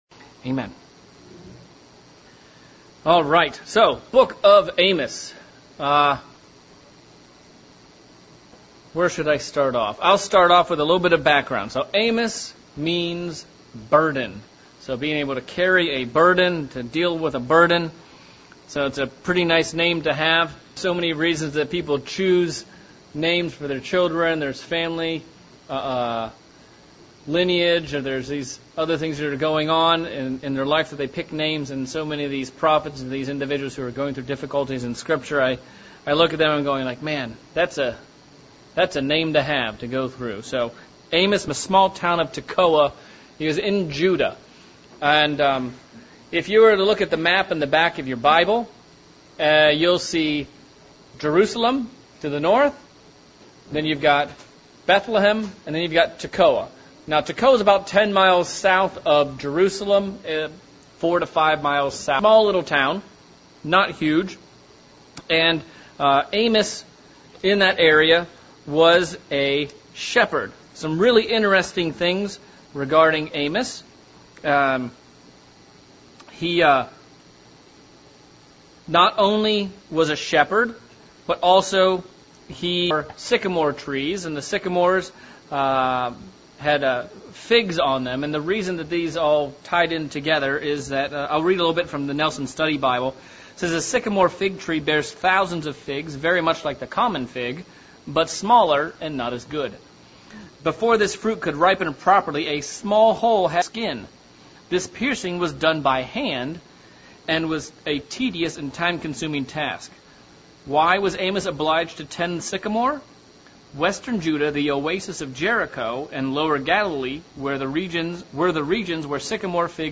August 2023 Bible Study - Amos Chapter 1